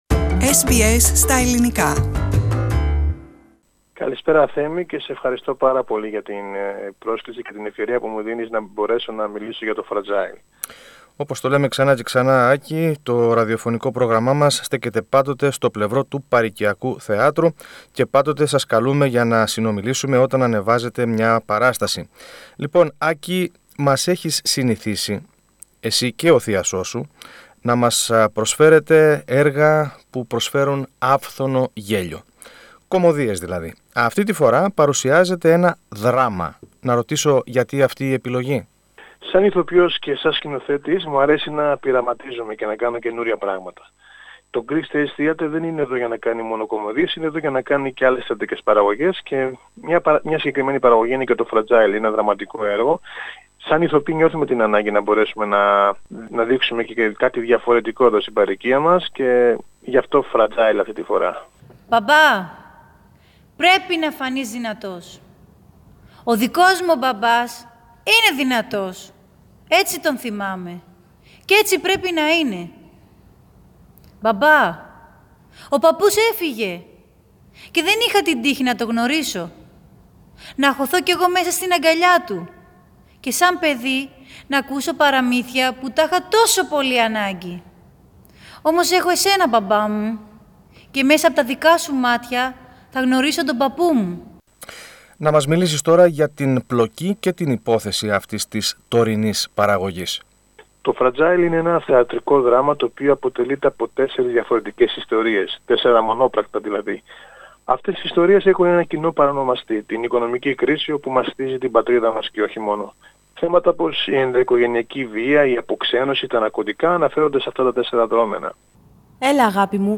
Ακούστε περισσότερα στη συνέντευξη που παραχώρησε στο πρόγραμμά μας